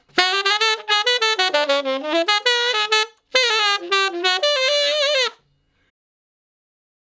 066 Ten Sax Straight (D) 35.wav